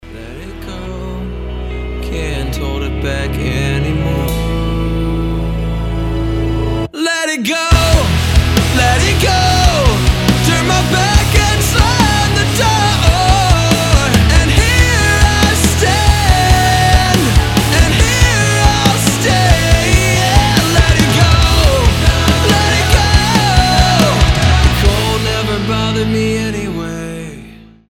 рок рингтоны